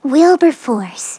synthetic-wakewords
ovos-tts-plugin-deepponies_Fluttershy_en.wav